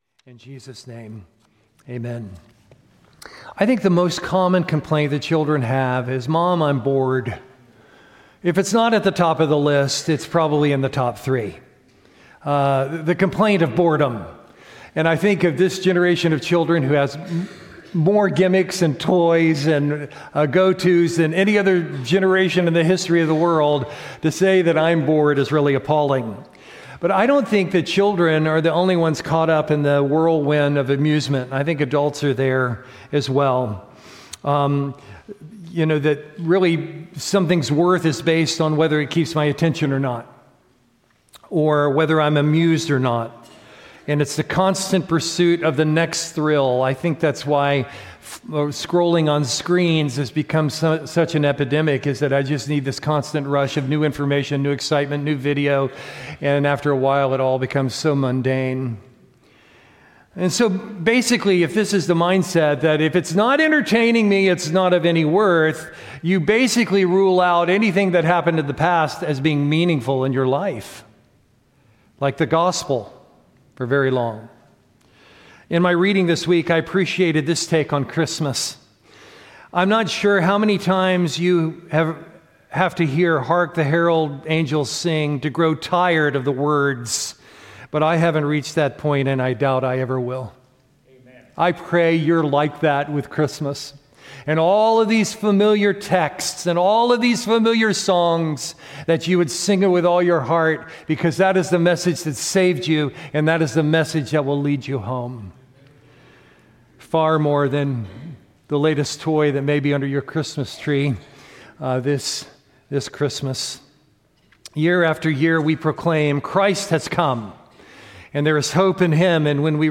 Sermon content from First Baptist Church Gonzales, LA